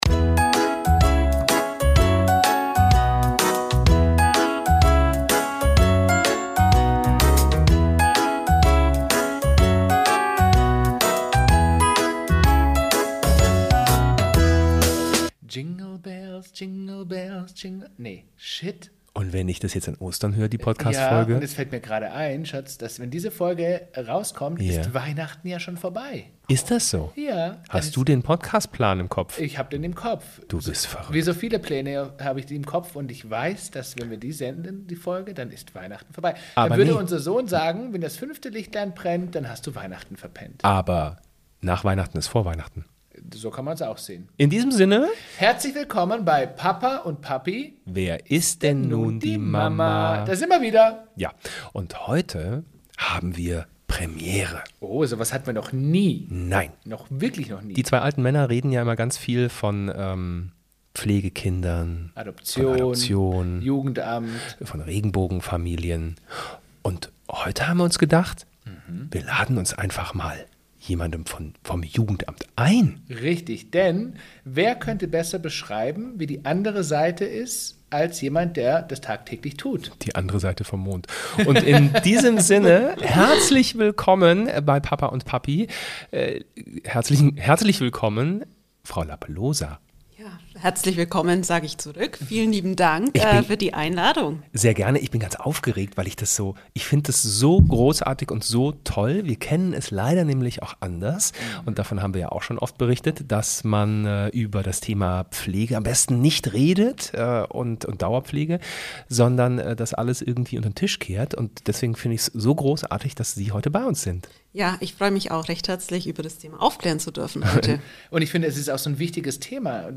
Ein ehrliches Gespräch für alle, die sich fragen, ob Pflegeelternschaft etwas für sie sein könnte.